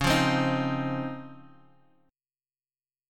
DbM9 Chord
Listen to DbM9 strummed